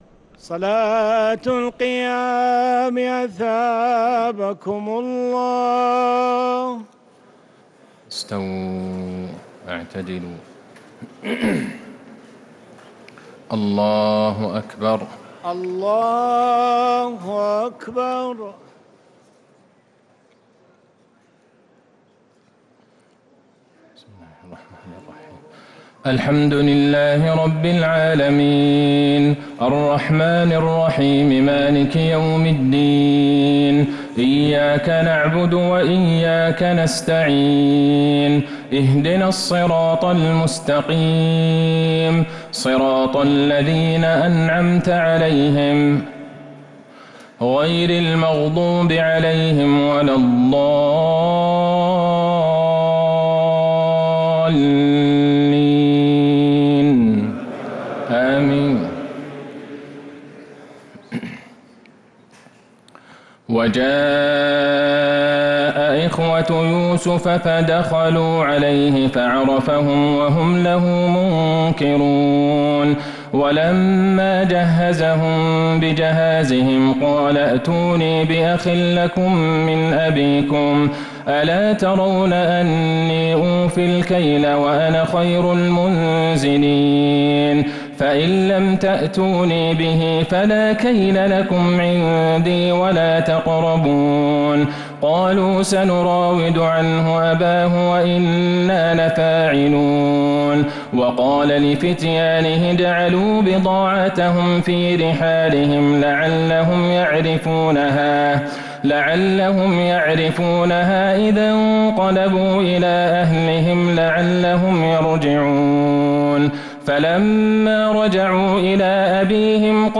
تراويح ليلة 17رمضان 1447هـ من سورتي يوسف (58) والرعد (1-18) | Taraweeh 17th night Ramadan 1447H Surah Yusuf and Al-raad > تراويح الحرم النبوي عام 1447 🕌 > التراويح - تلاوات الحرمين